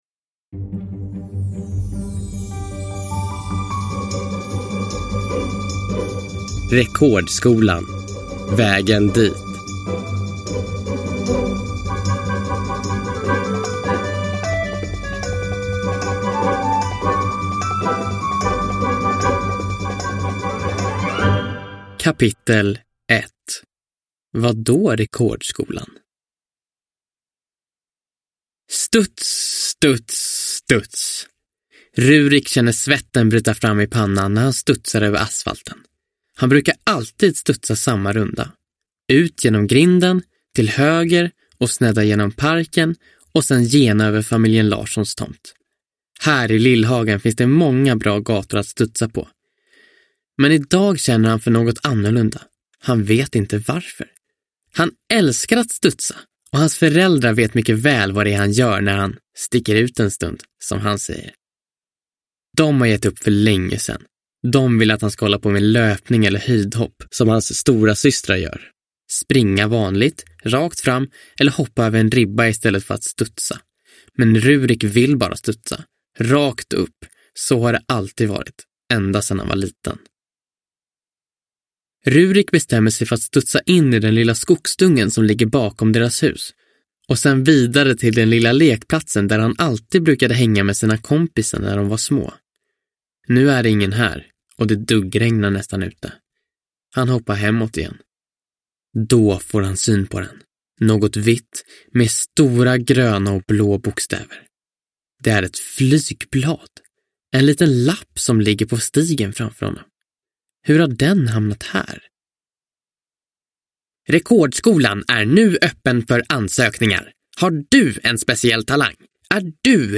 Rekordskolan : Vägen dit – Ljudbok – Laddas ner